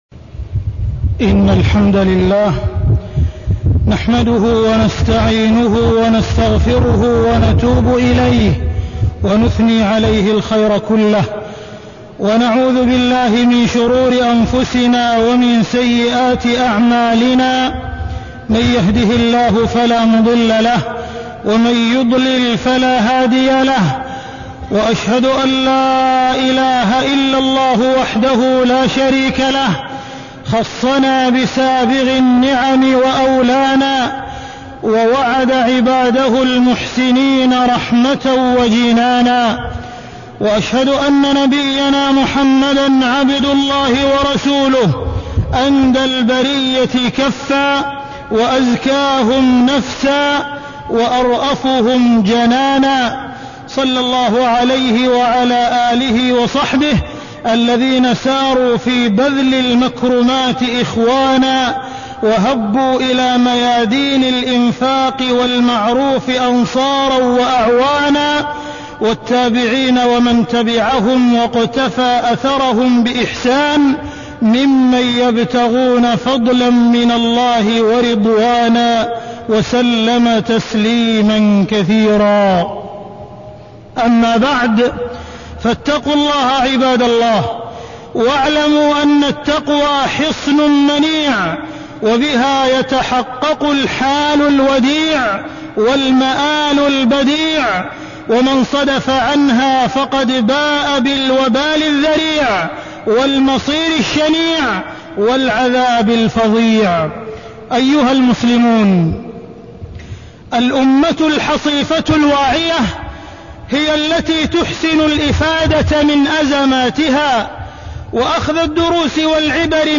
تاريخ النشر ١ ربيع الأول ١٤٢٤ هـ المكان: المسجد الحرام الشيخ: معالي الشيخ أ.د. عبدالرحمن بن عبدالعزيز السديس معالي الشيخ أ.د. عبدالرحمن بن عبدالعزيز السديس الإنفاق في سبيل الله